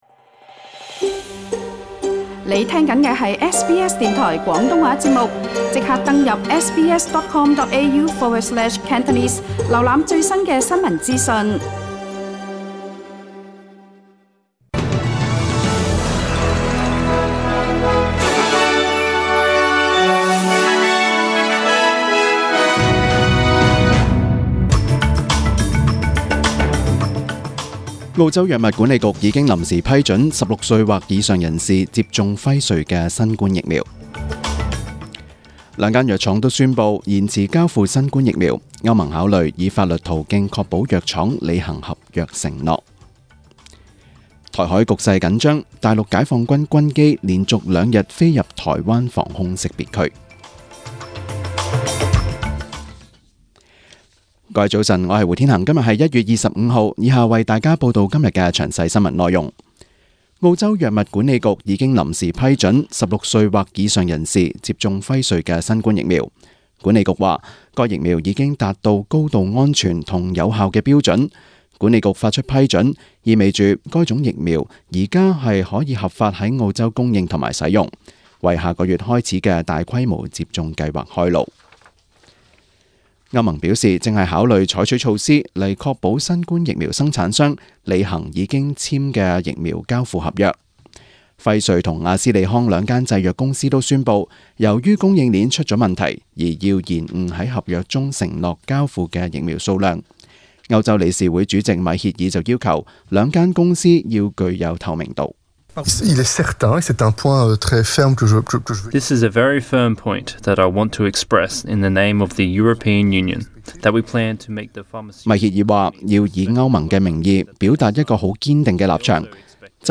SBS 中文新聞 （一月二十五日）